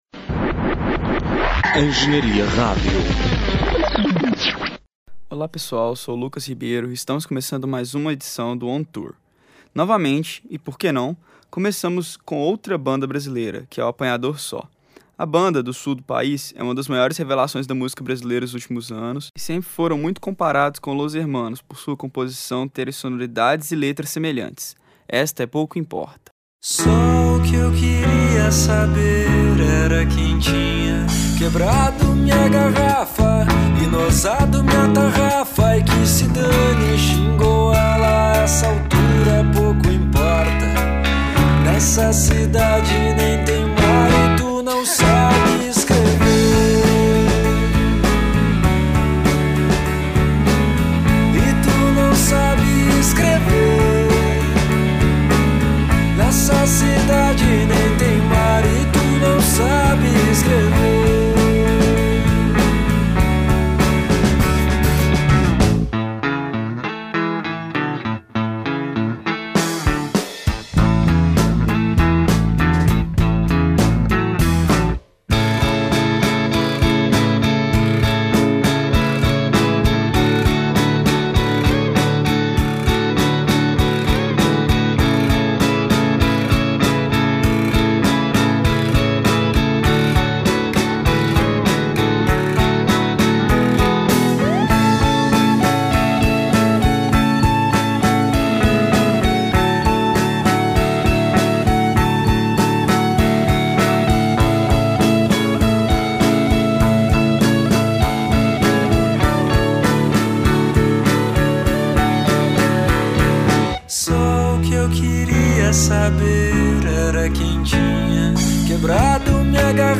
através da voz com sotaque brasileiro